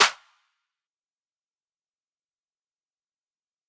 Metro Claps [Mode].wav